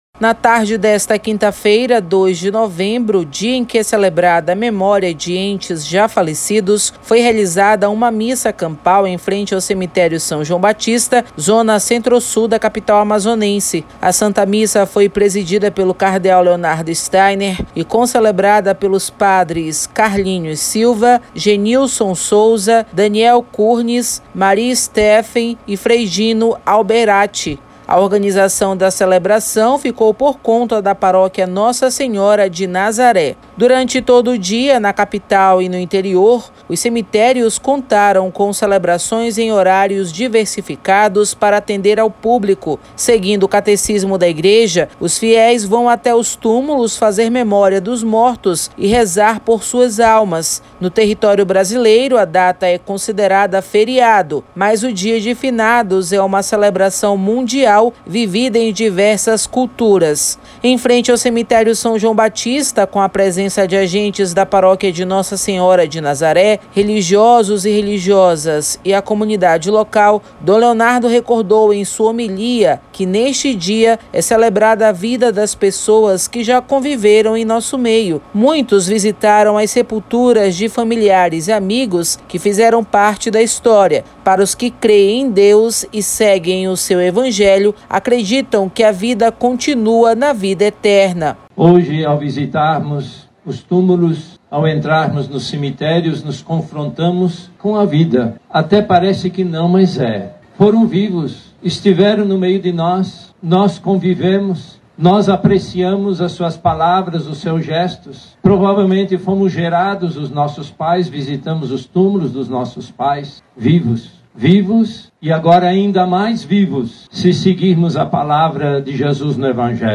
Na tarde desta quinta-feira, 2 de novembro, dia em que é celebrada a memória de entes já falecidos, foi realizada uma Missa Campal em frente ao cemitério São João Batista, zona centro-sul da capital amazonense.